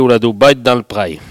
Langue Maraîchin
Catégorie Locution ( parler, expression, langue,... )